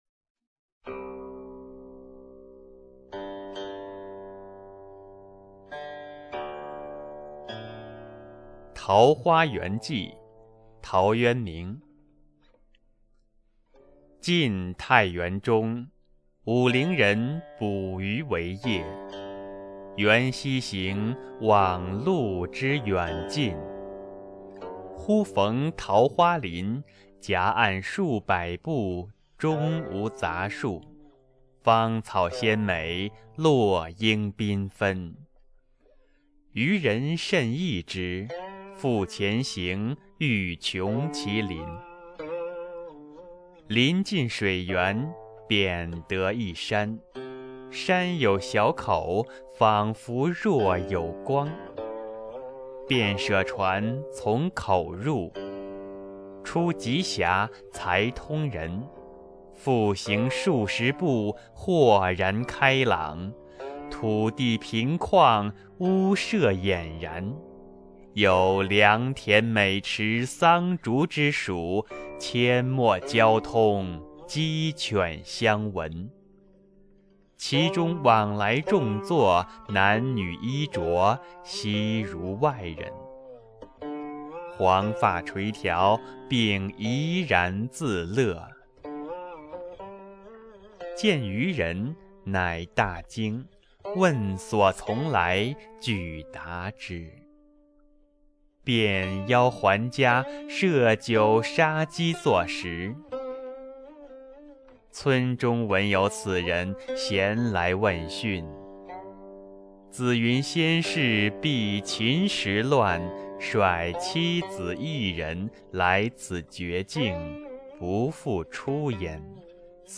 《桃花源记》课文朗读